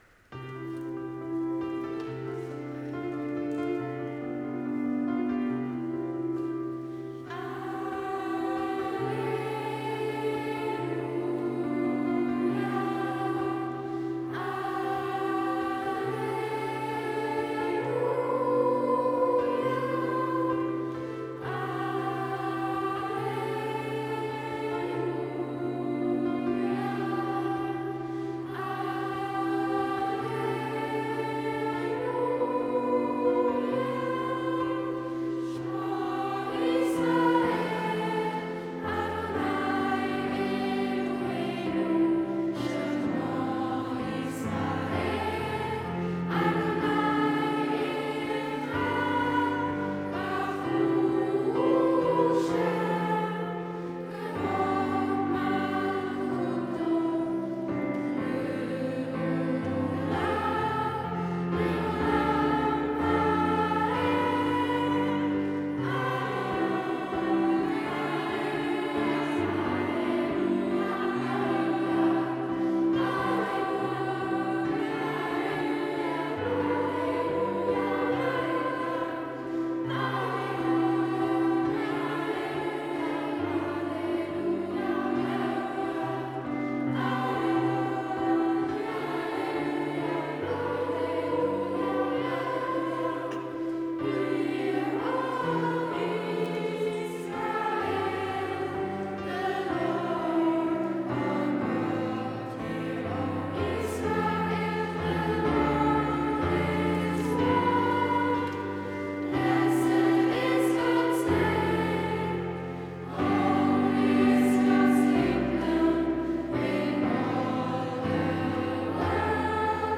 Weihnachtskonzert 2021
Immerhin konnten die einzelnen Stücke in der Gebhardskirche aufgenommen werden und stehen hier für Sie bereit.
Unterstufenchor
Unterstufenchor Sh_Ma Yisrael_cut3.wav